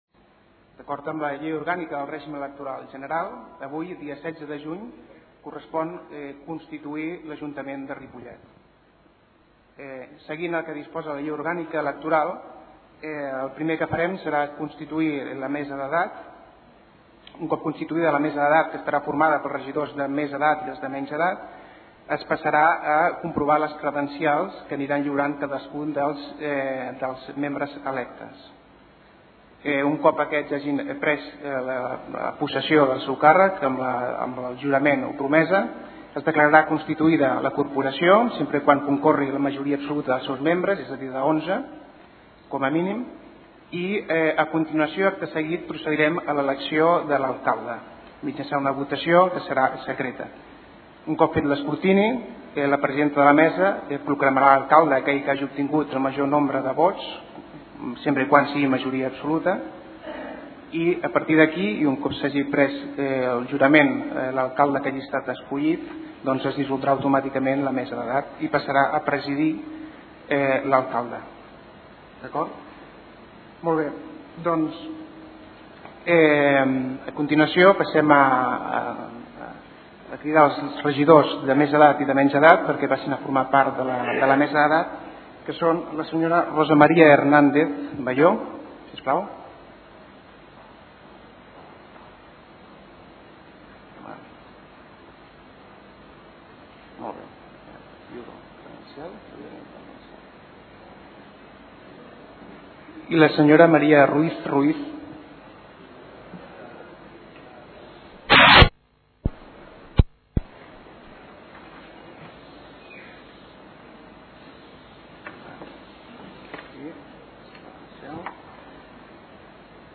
Descarregar arxiu ripollet-ple-constitucio-160607.mp3
Política Juan Parralejo, de nou alcalde -Política- 18/06/2007 Juan Parralejo va ser reelegit alcalde en el Ple de constituci� del nou Ajuntament, celebrat el passat dissabte 16 de juny.